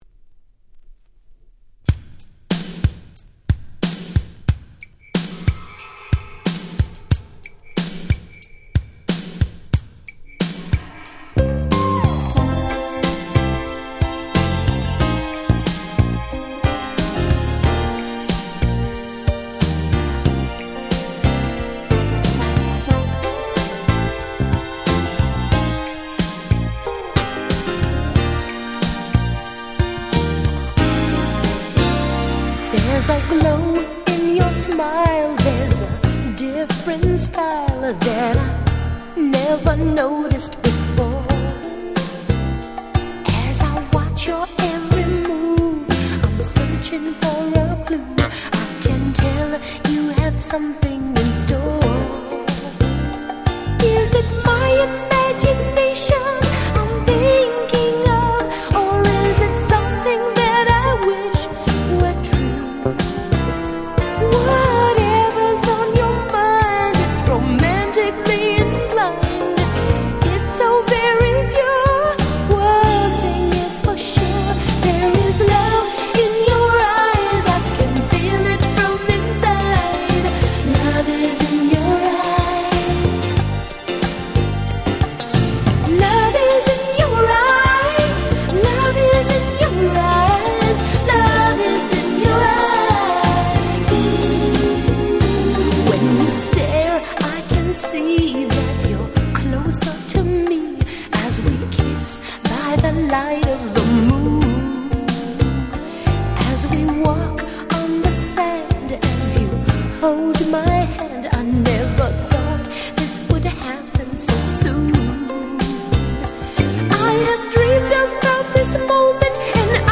88' Very Nice Mid / Slow R&B !!
両面共に最高のSlow / Mid。
スロウジャム 80's バラード